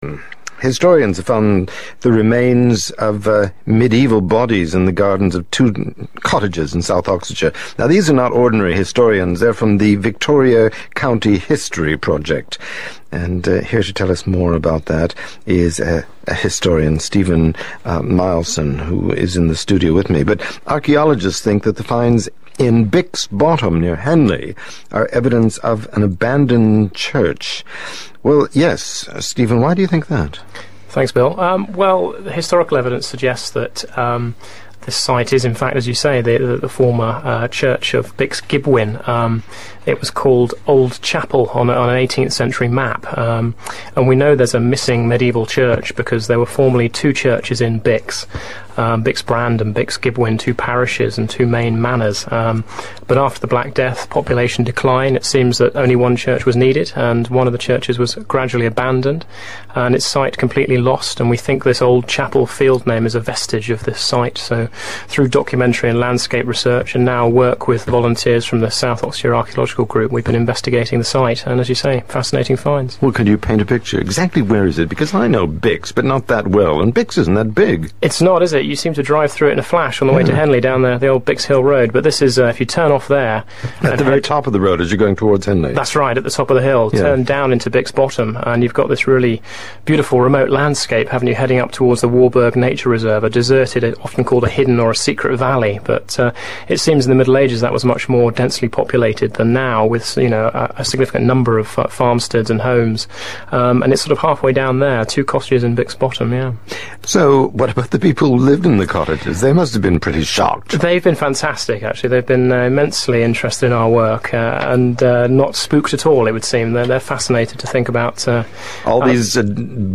interview 2 - historical background (audio in mp3 format - 6MB).